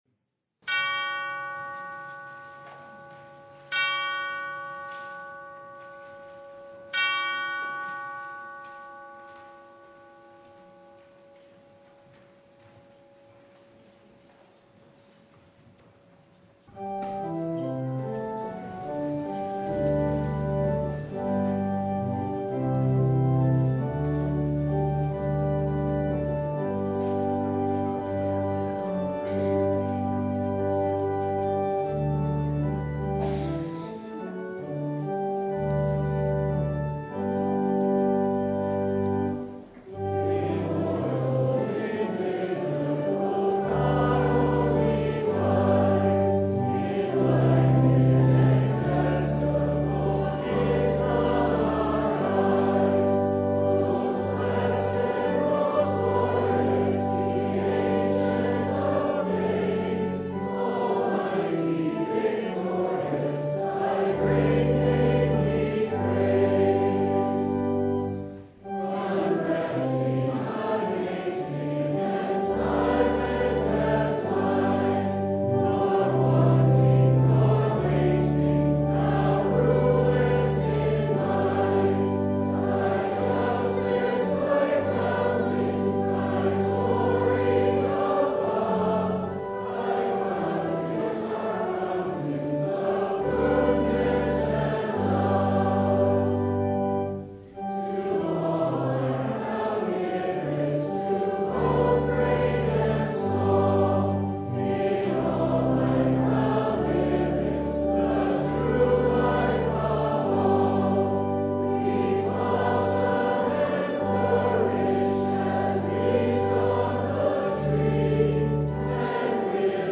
Luke 16:19-31 Service Type: Sunday You can’t take it with you.